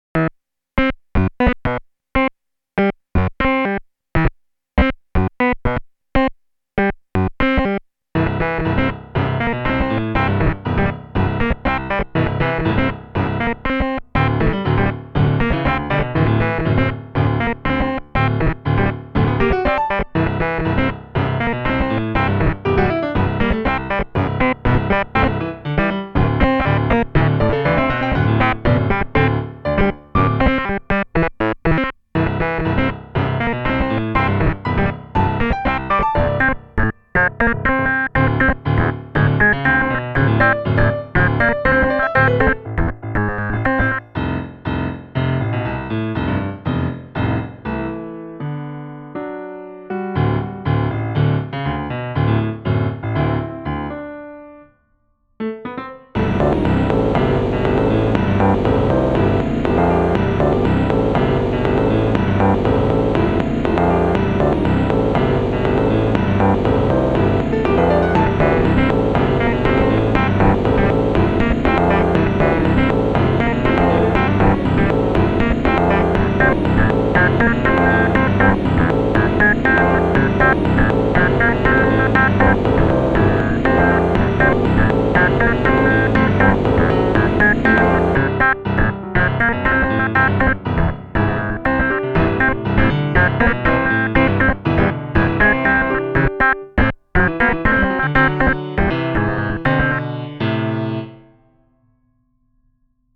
This isn't the same Winamp piano as above -- it's a sampled concert grand, but "played" with very little nuance.